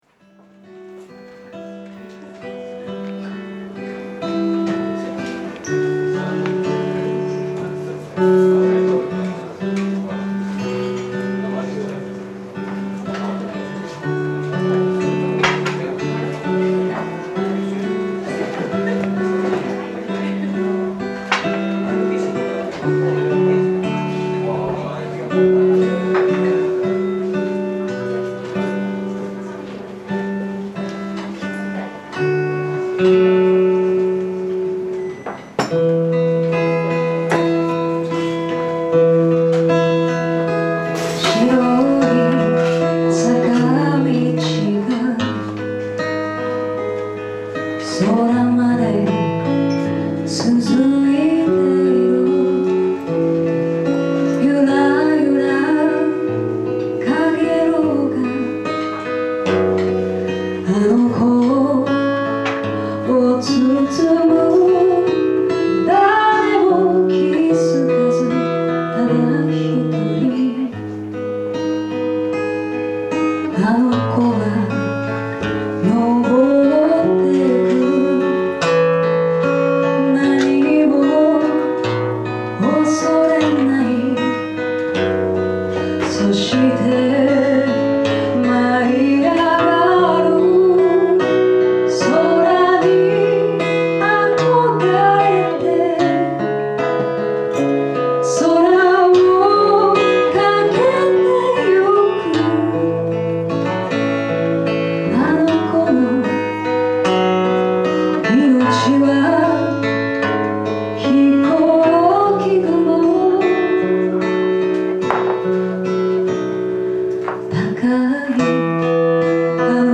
今日はライブバージョンでどうぞ。